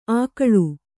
♪ ākaḷu